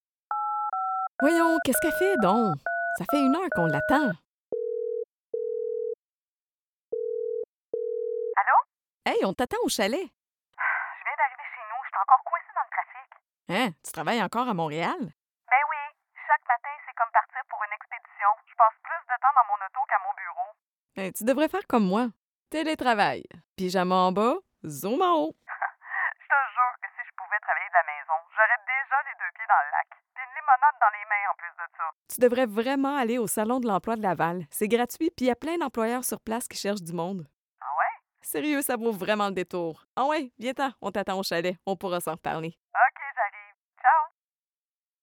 Female
French (Canadian)
Yng Adult (18-29), Adult (30-50)
Conversational E-Learning Demo
0529De_la_conversation_2.mp3